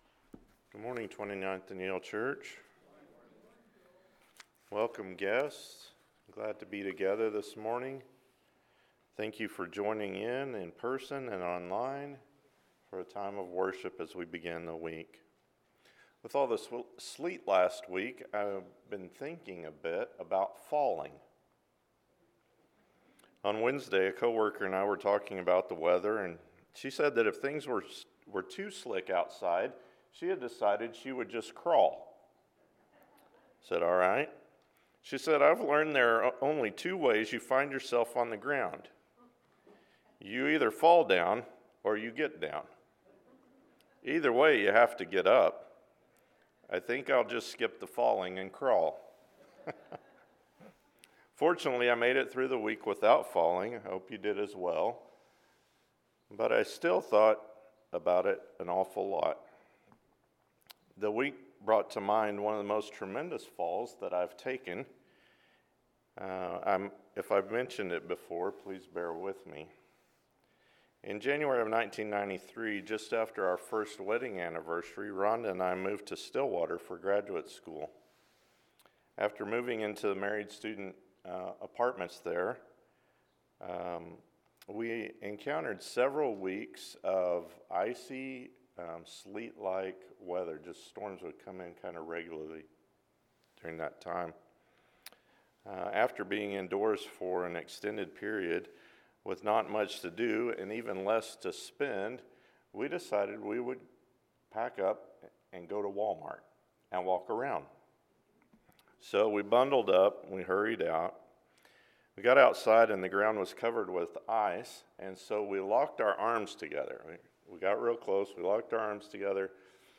New, Old Language for Prayer – Psalms – Sermon